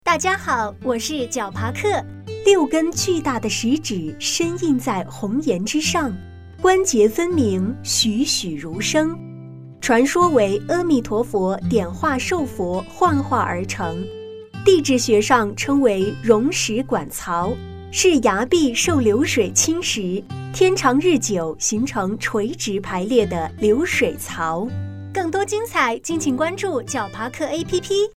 神仙指----- 66666 解说词: 六根巨大的石指深印在红岩之上，关节分明，栩栩如生；传说为阿弥陀佛点化寿佛幻化而成。